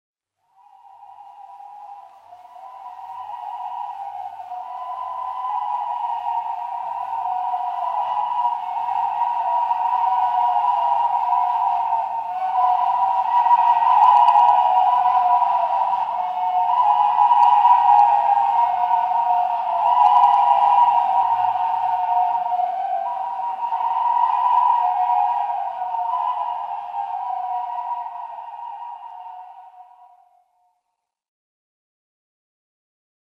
Звуки перелётных птиц
Крик журавлей улетающих на юг, летящих в небе